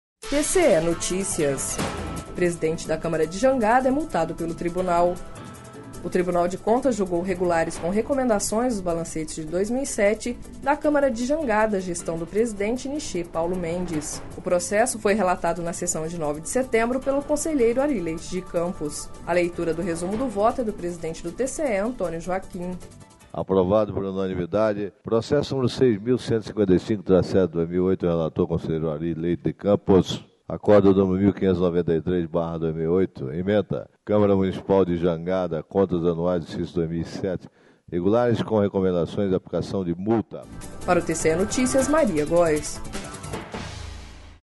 A leitura do resumo do voto é do presidente do TCE, Antonio Joaquim.// Sonora: Antônio Joaquim – conselheiro presidente do TCE-MT